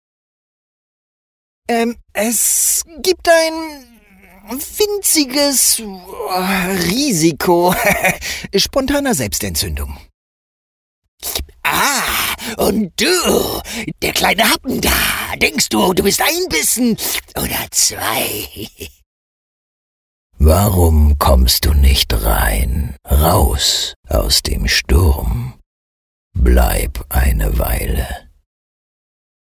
Male
Spanish (Latin American)
Animation